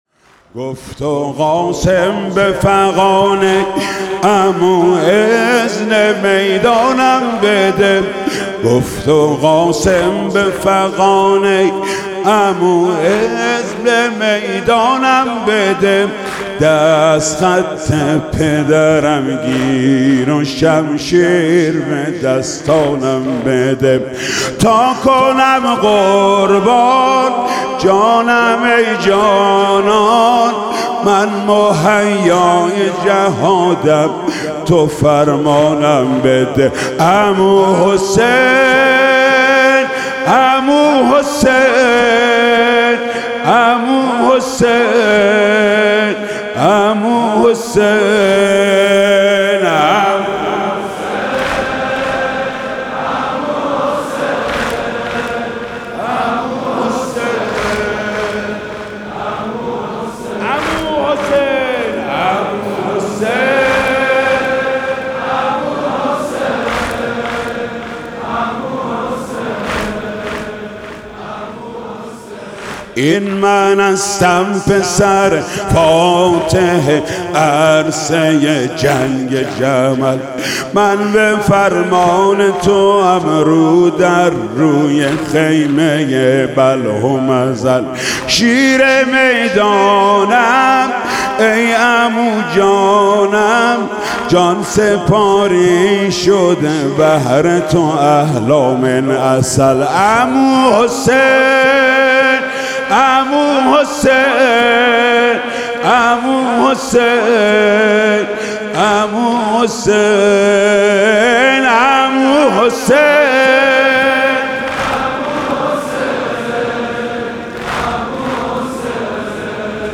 مداحی محمود کریمی در شب ششم محرم - تسنیم
مراسم شب ششم ماه محرم در هیئت رایة العباس با مداحی محمود کریمی برگزار شد.
در ادامه، صوت و فیلم مداحی و سینه‌زنی این مراسم را مشاهده می‌کنید.